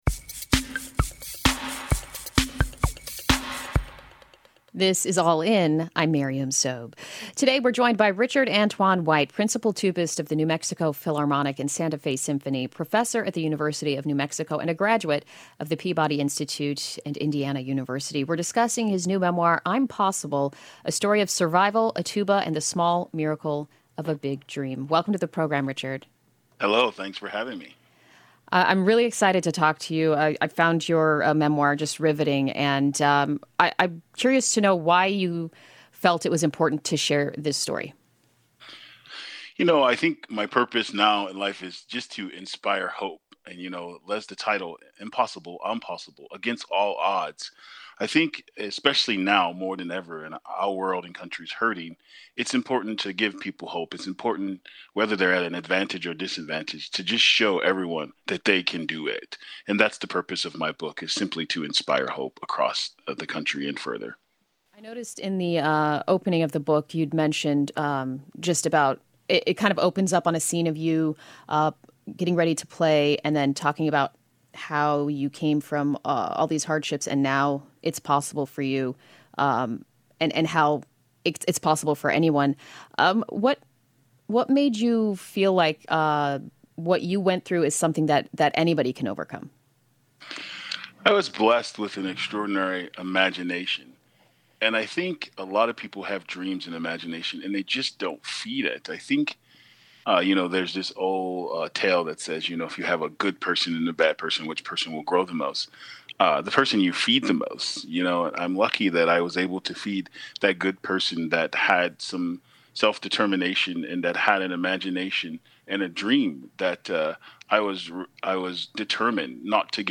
Listen to the broadcast version of the show